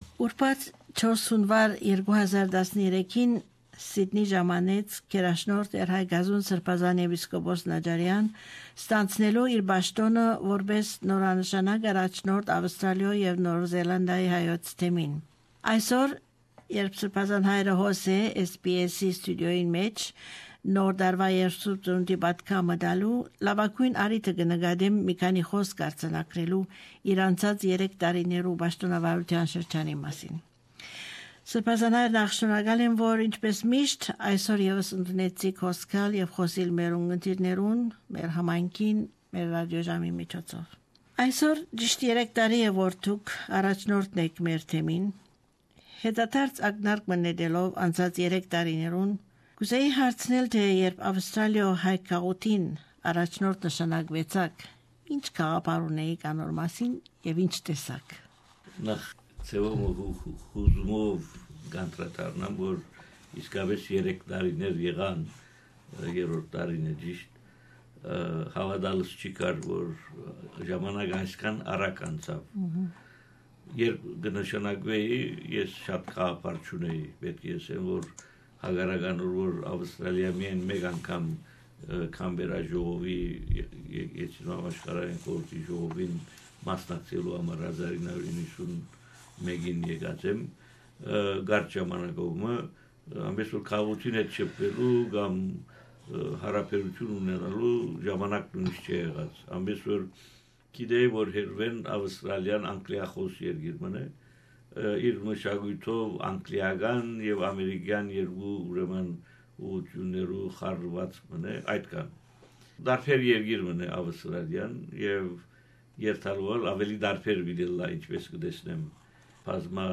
Հարցազրոյց Տ. Հայկազուն Եպիսկոպոս Նաճարեանի հետ - Ա մաս
Հարցազրոյց Տէր Հայկազուն Եպիսկոպոս Նաճարեանի հետ իր Աւստրալիոյ եւ Նոր Զելանտայի Հայոց Թեմին Առաջնորդ նշանակուելուն երրորդ տարեդարձին առիթով: Ա մասով՝ յետադարձ ակնարկ մը անցած երեք տարիներուն. աղանդները ինչպէս մուտք կը գործեն ընկերութիւններէ ներս եւ, կրօնական դաստիարակութիւնը դպրոցներէ ներս: